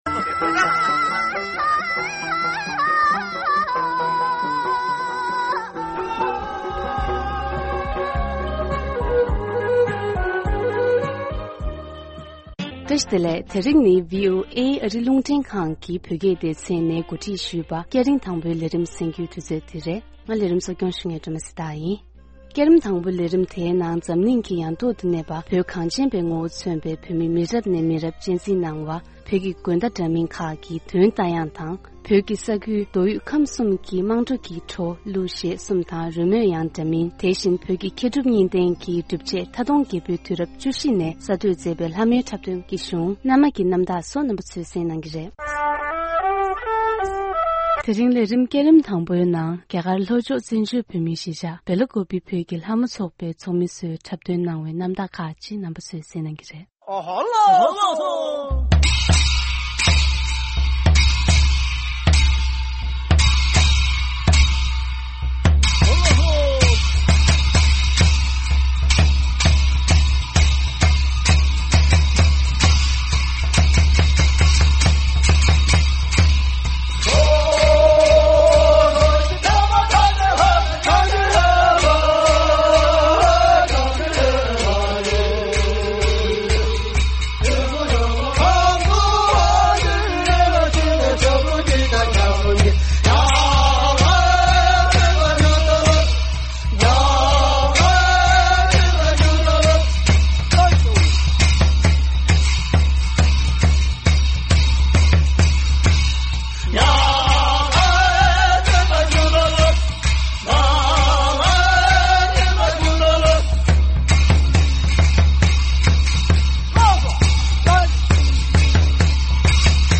This episode plays dance performed by members of the Lhamo dance group in Bylakupee